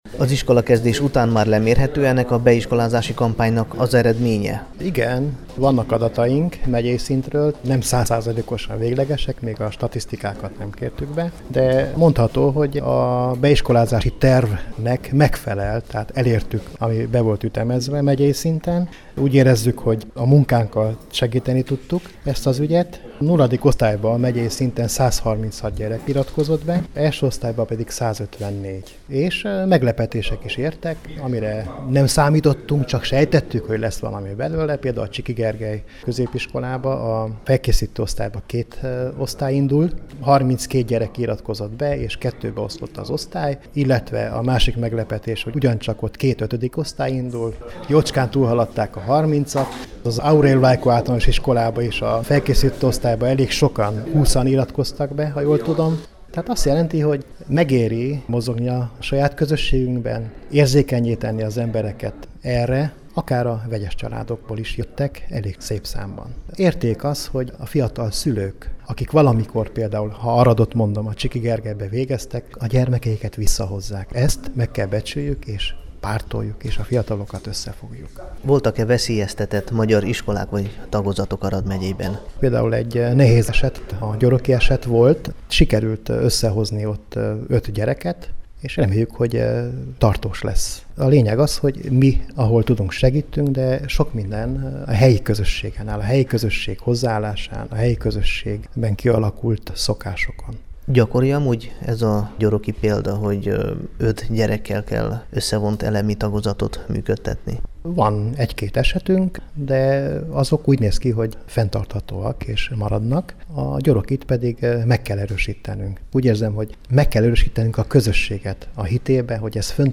interjúban